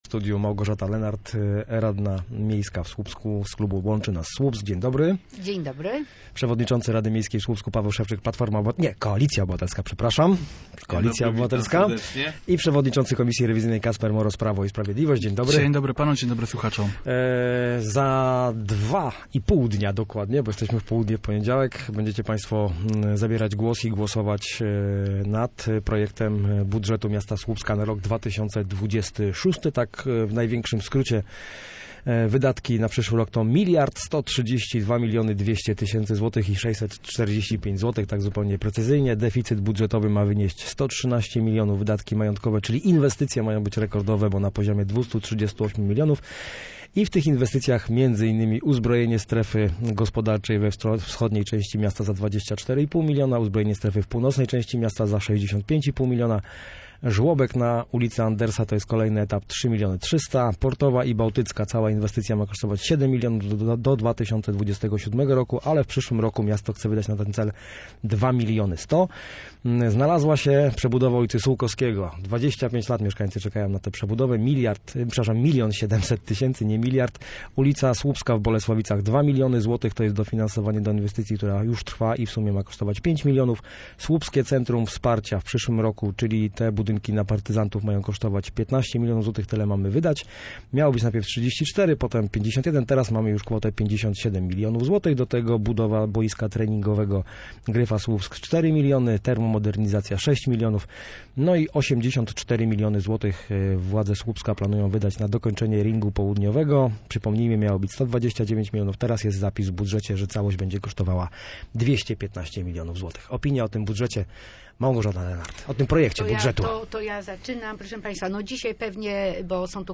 Dyskusja wokół budżetu Słupska na 2026 rok.
Projekt budżetu Słupska na rok 2026 trzeba zmienić – uważa przewodniczący rady miasta. Paweł Szewczyk z Koalicji Obywatelskiej w Studiu Słupsk wskazał, że w budżecie muszą się znaleźć pieniądze na odkupienie działek na potrzeby budowy nowej hali widowiskowo-sportowej obok parku wodnego Trzy Fale.